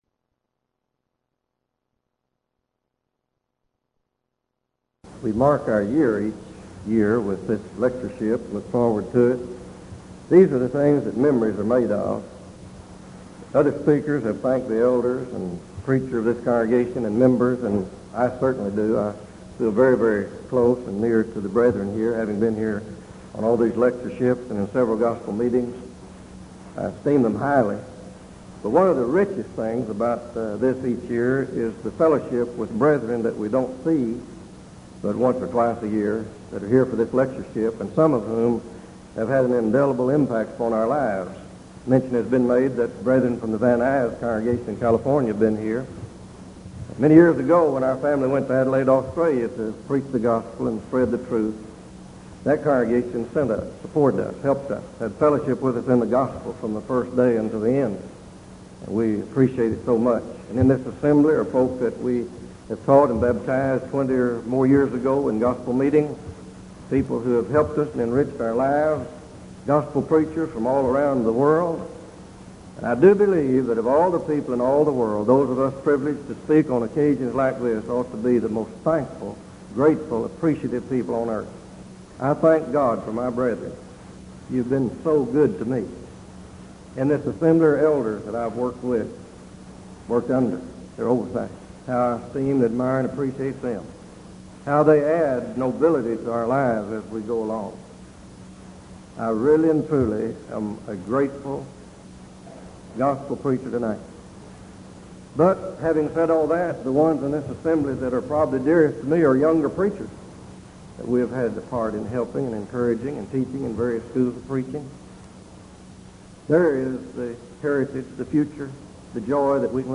Event: 1986 Denton Lectures